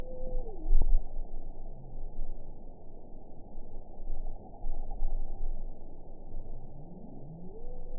event 922432 date 12/31/24 time 23:13:06 GMT (11 months ago) score 5.25 location TSS-AB06 detected by nrw target species NRW annotations +NRW Spectrogram: Frequency (kHz) vs. Time (s) audio not available .wav